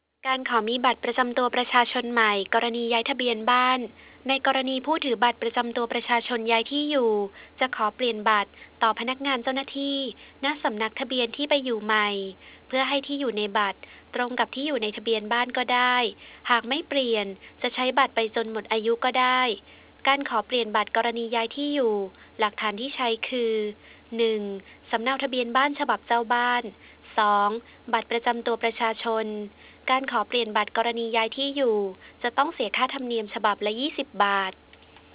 (เสียงบรรยายประกอบ)